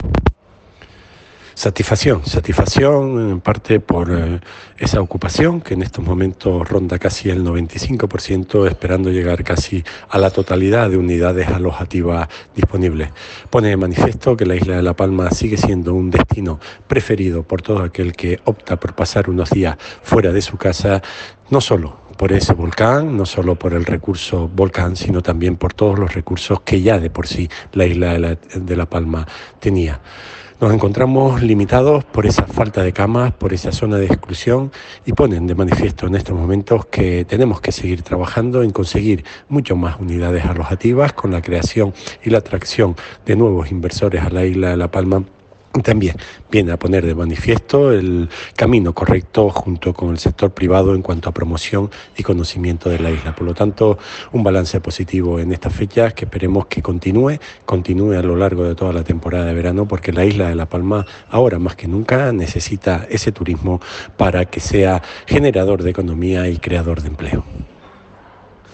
Declaraciones_audio_Raúl_Camacho_Semana_Santa.mp3